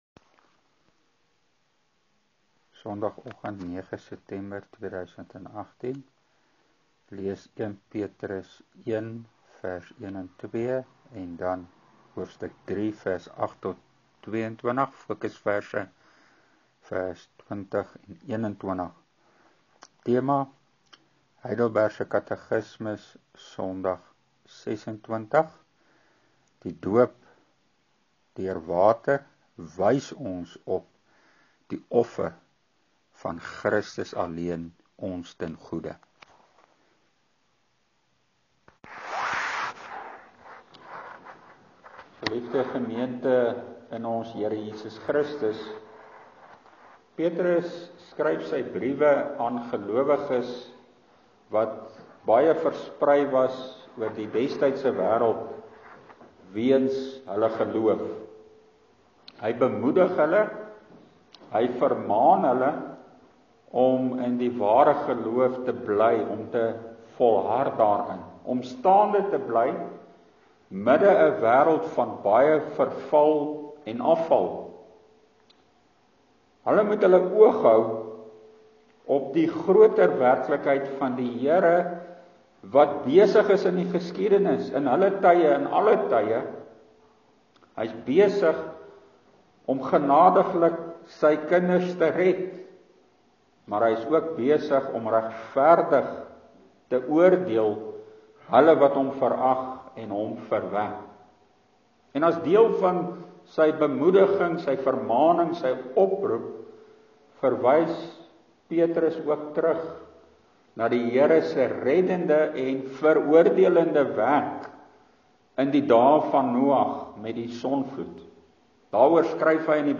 Preek: Die heilige doop wys ons op Christus se reddingswerk